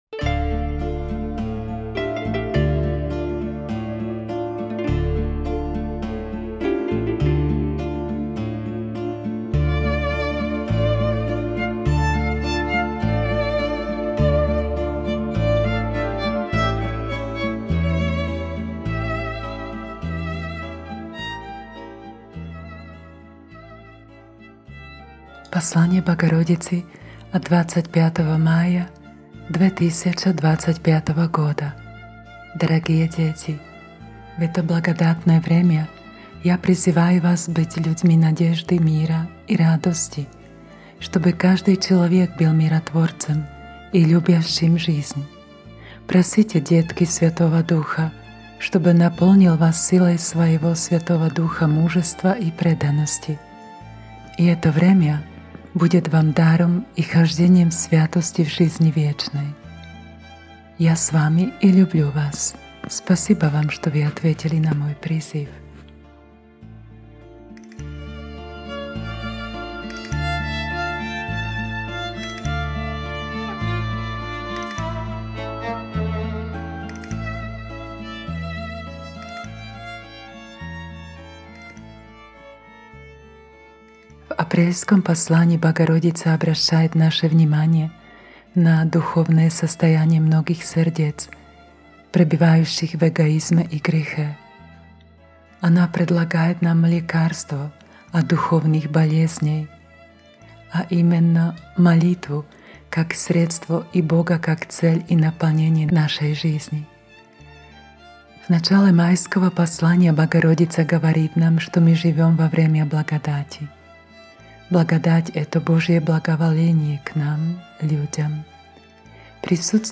Звукозапись размышлений над посланием от 25.03.2026